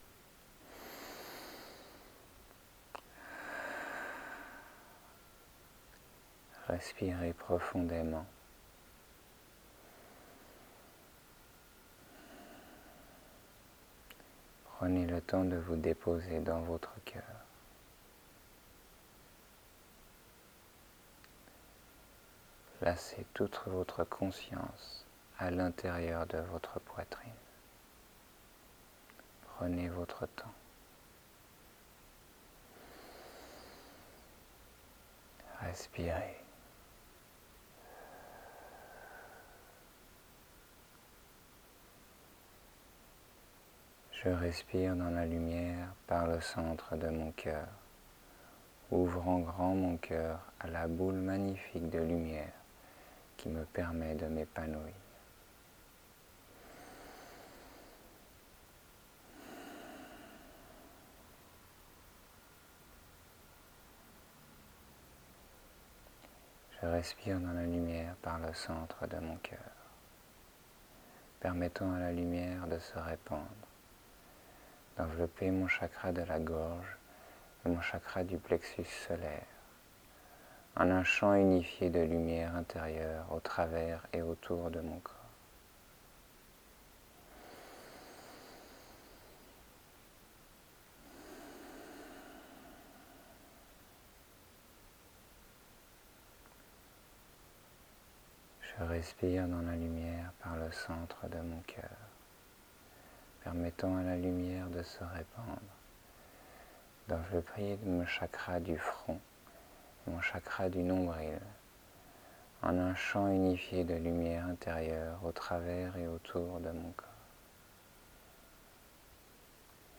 Durant cette méditation,vous êtes guidés à ouvrir et harmoniser vos champs d’énergie afin d’unifier et d’aligner tout votre être sur tout les plans.